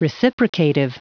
Prononciation du mot reciprocative en anglais (fichier audio)
Prononciation du mot : reciprocative